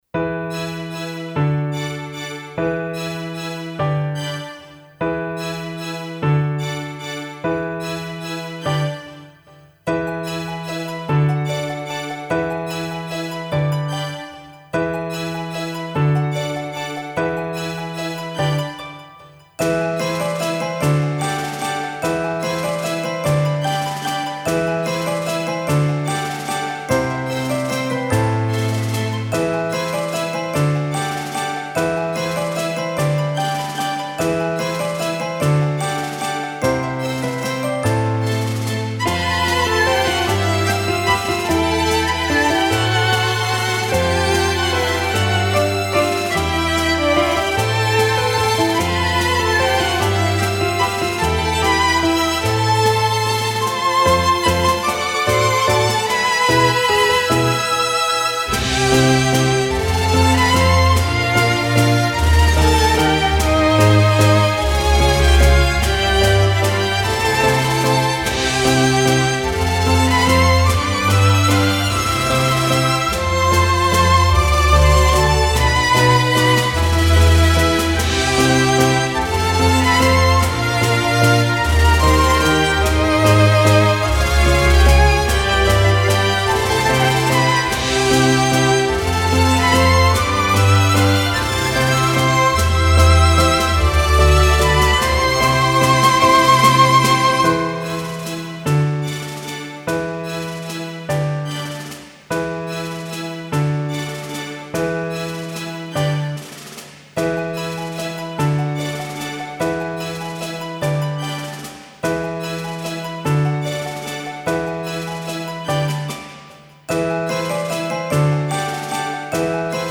フリーBGM 街・拠点・村など 優雅
フェードアウト版のmp3を、こちらのページにて無料で配布しています。